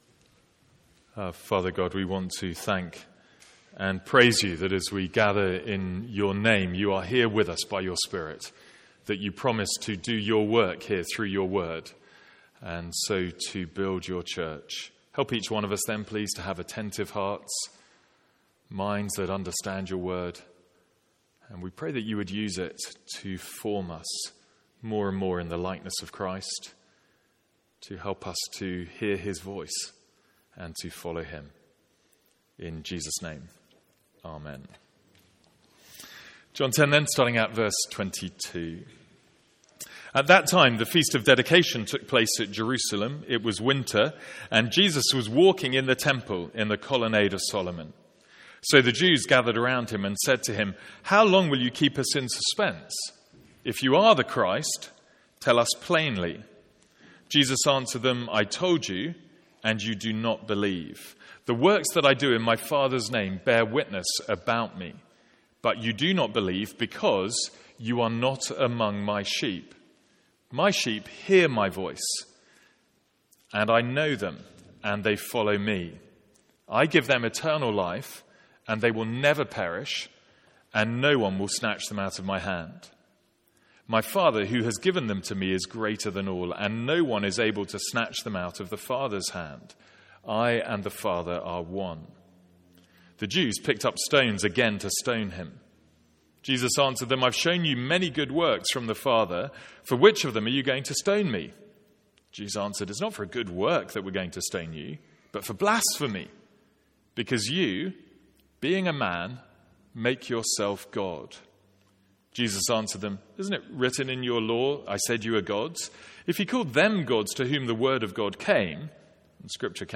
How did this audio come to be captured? From our morning series in John's Gospel.